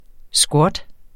Udtale [ ˈsgwʌd ]